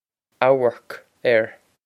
Amharc ar Ow-erk err
Ow-erk err
This is an approximate phonetic pronunciation of the phrase.